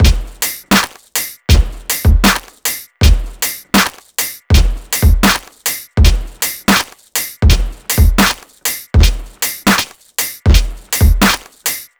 • 80 Bpm Drum Groove E Key.wav
Free drum loop sample - kick tuned to the E note. Loudest frequency: 3299Hz
80-bpm-drum-groove-e-key-CIK.wav